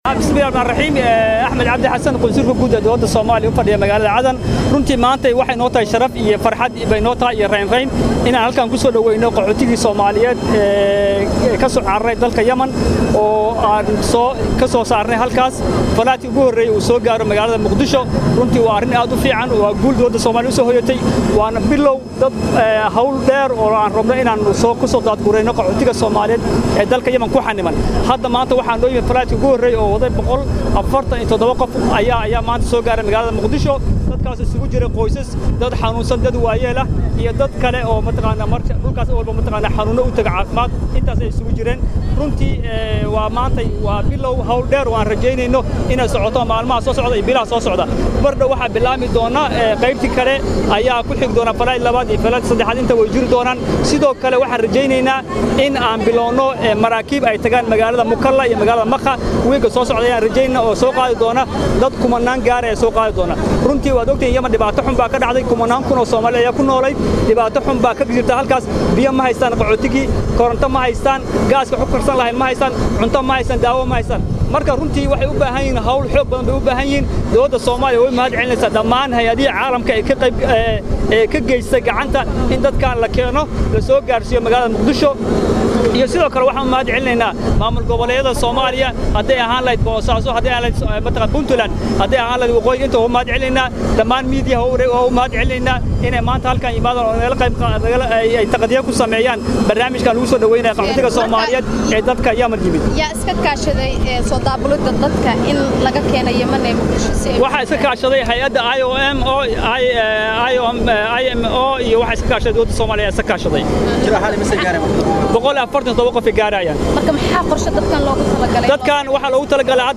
Waraysiga Qunsulka Cadan